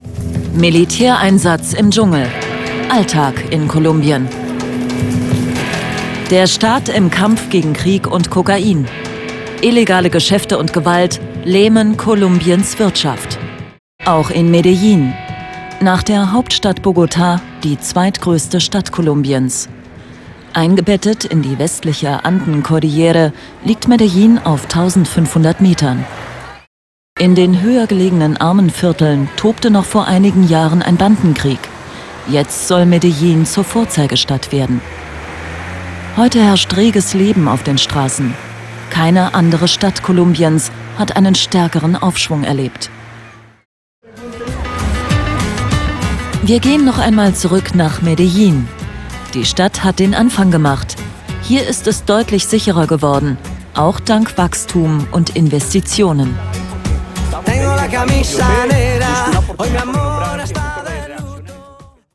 Stimme: Warm, charismatisch, charmant.
Sprechprobe: Industrie (Muttersprache):